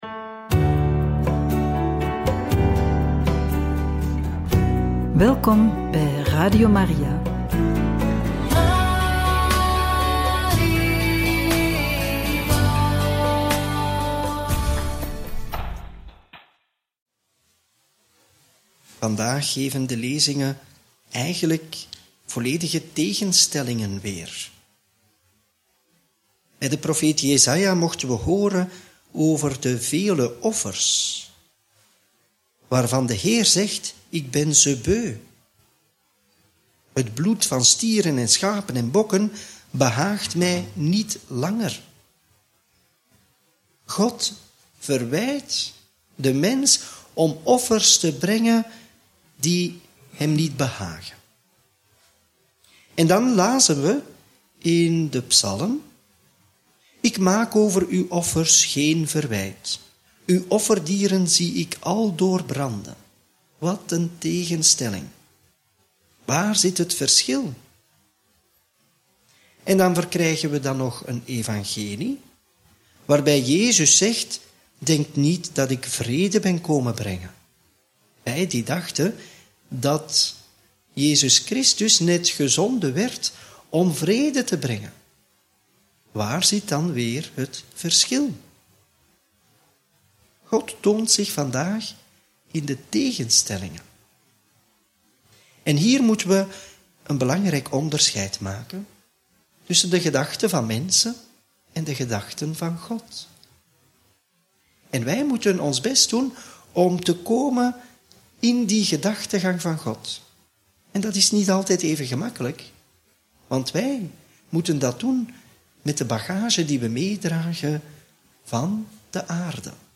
Homilie bij het Evangelie op maandag 15 juli 2024 (Mt. 10, 34 -11, 1)